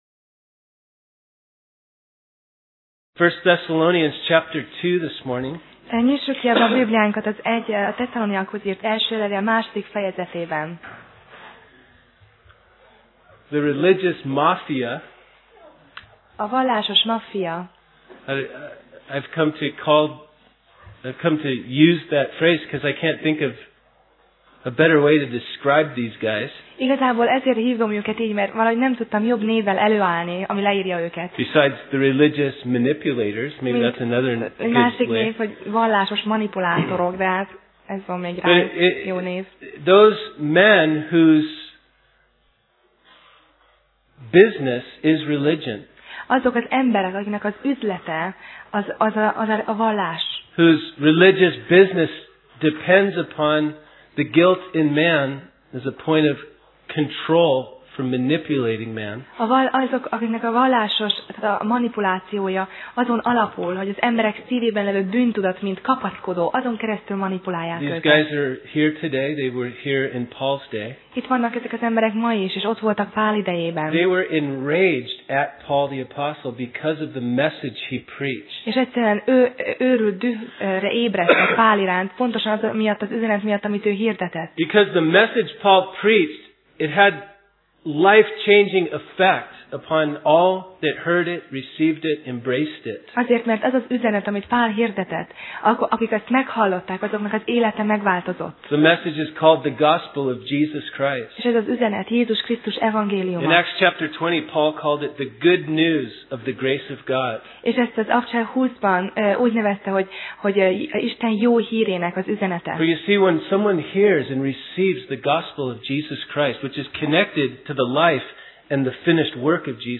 Sorozat: 1Thesszalonika Passage: 1Thessz (1Thess) 2:1-12 Alkalom: Vasárnap Reggel